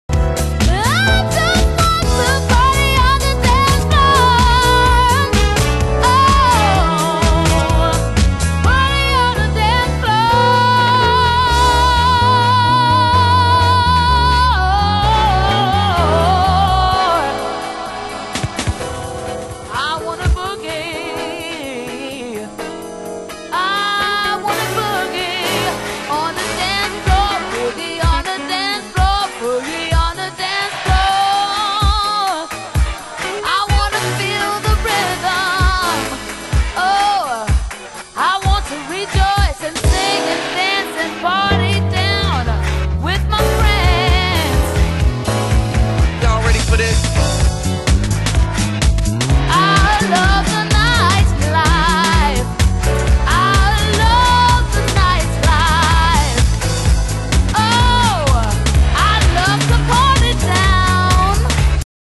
盤質：概ね良好、少しチリパチノイズ有　　ジャケ：概ね良好/インナースリーブに一部破れ